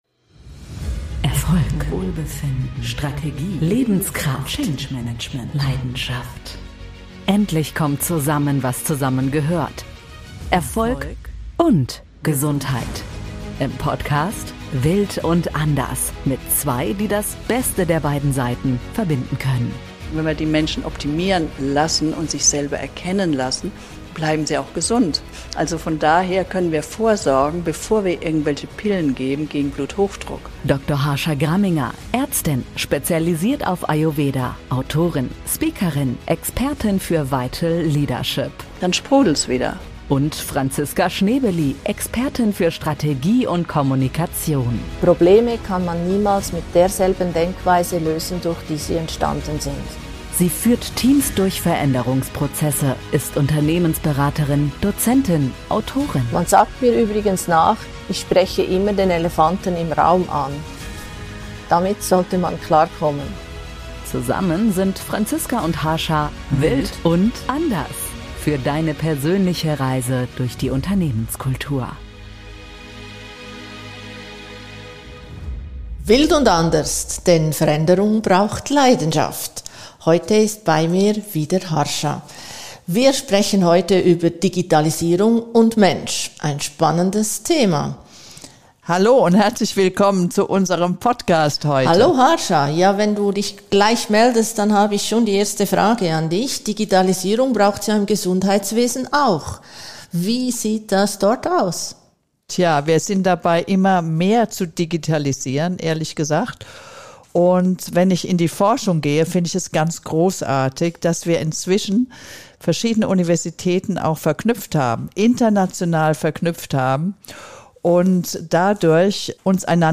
Die beiden sprechen in dieser Folge über die Vorteile der Digitalisierung und darüber, in welchen Bereichen diese wirklich Sinn macht.